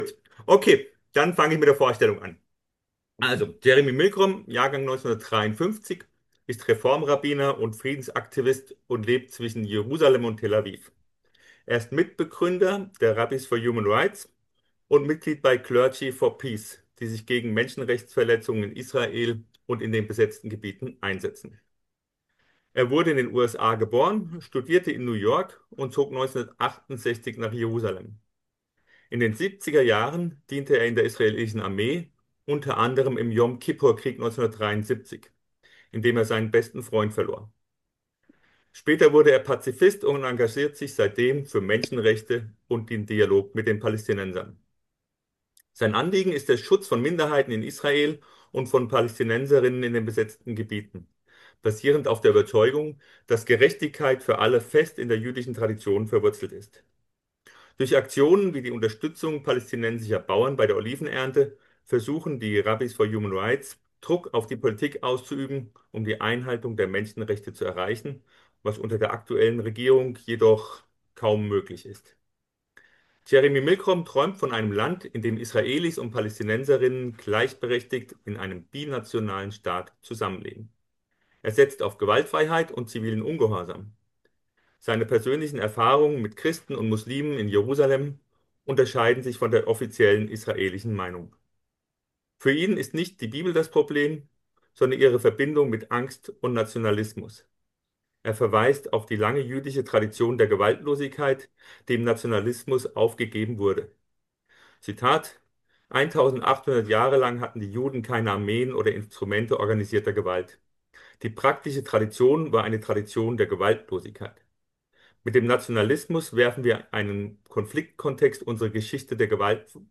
Pax Christi - Meldungen - Vortrag